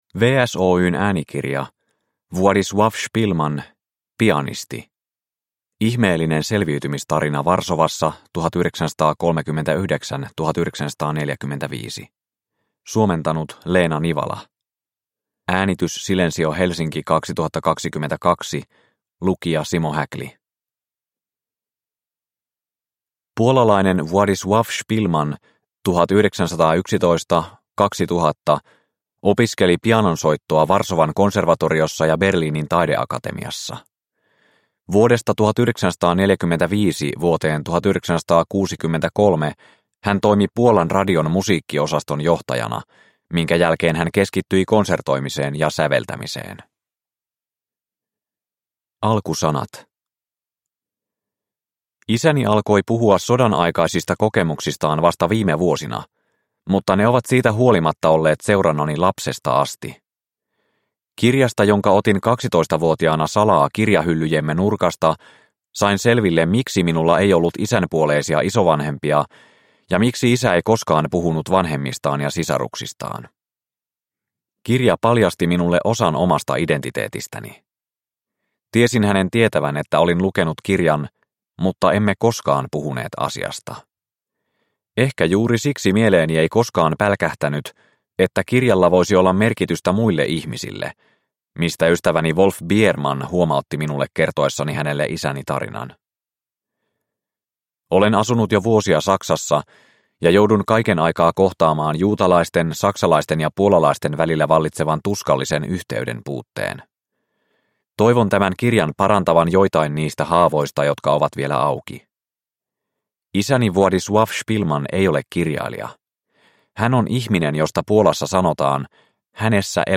Pianisti – Ljudbok – Laddas ner